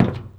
Footstep_Metal 01.wav